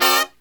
FALL HIT05-L.wav